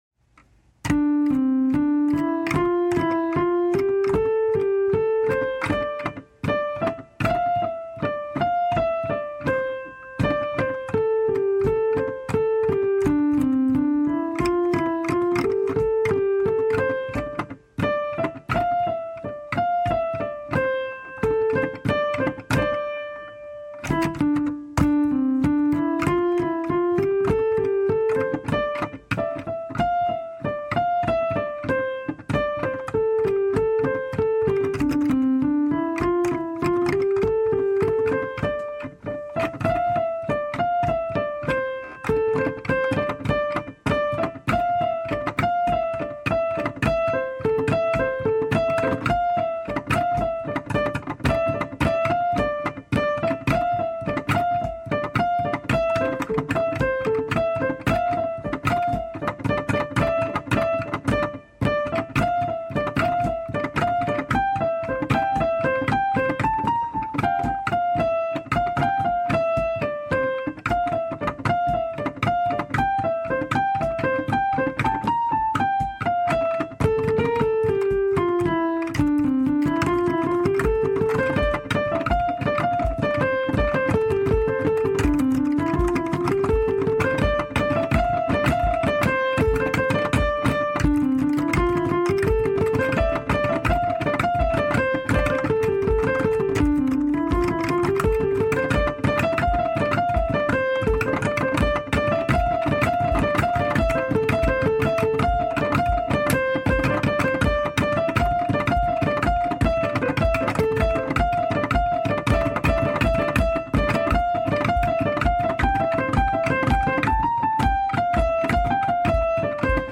So this afternoon I added a third part to go in between the two parts I wrote before.